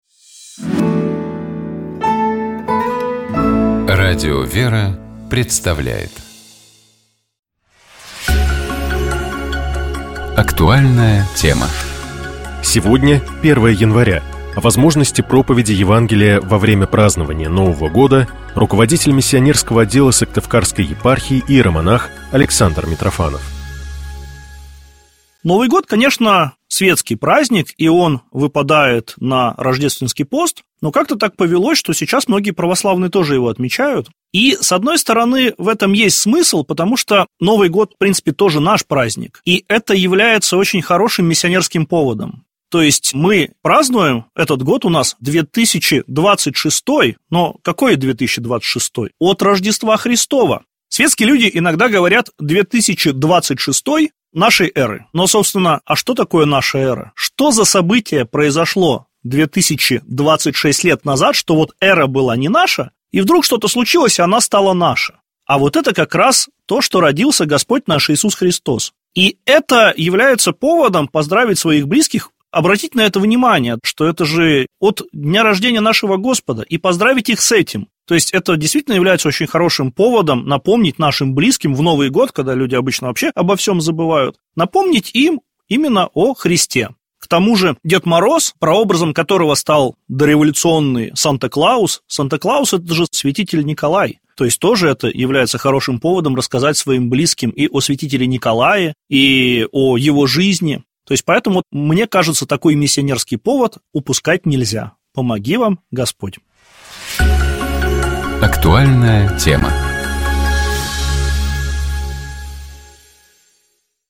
ГлавнаяПрограммыБогослужебные песнопения
Послушаем задостойник Преображения в исполнении хора Института певческой культуры «Валаам».